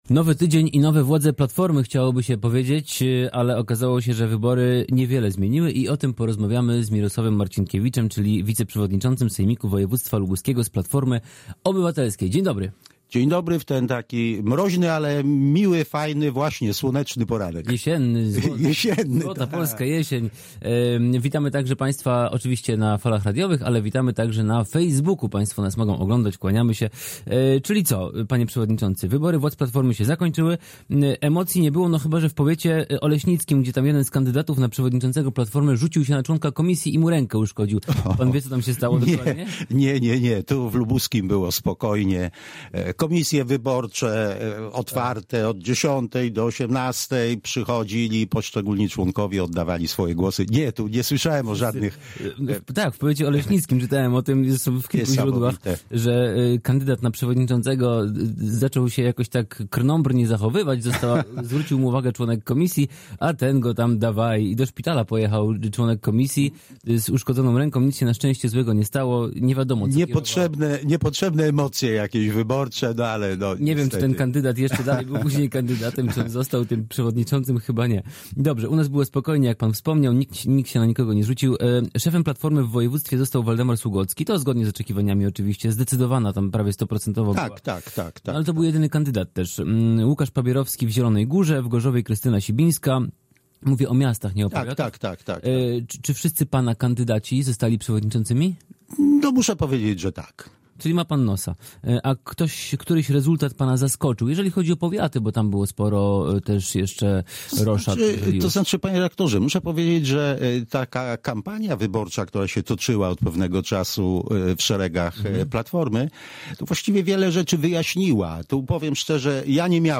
Z wiceprzewodniczącym sejmiku województwa lubuskiego z PO rozmawia